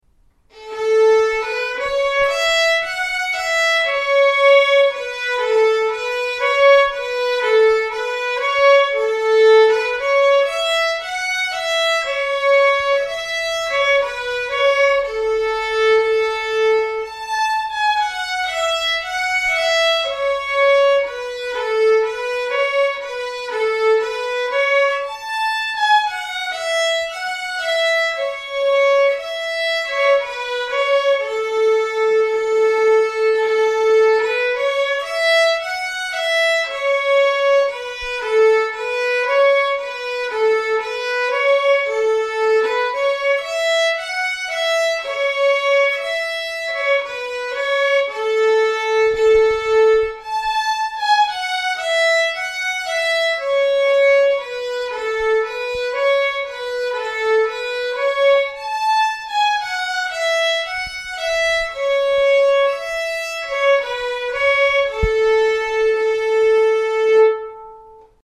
Throughout the duration of UnstFest, we will be recording some of the many events that are scheduled.
Fiddle Workshop - 10.07.09
Keen Fiddlers Being Put Through Their Paces Learning A New Tune
Fiddle Workshop Sample 3